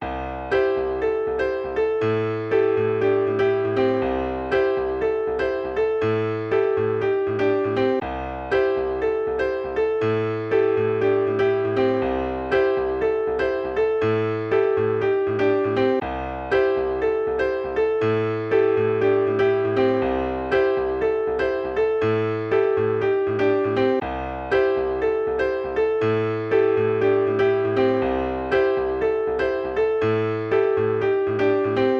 Вот сделал тест Gold2 (H073) 700 hz +9.2db и цифровой eq немного подогнан, фаза не точно такая же, но похожа. Угадаете где переход?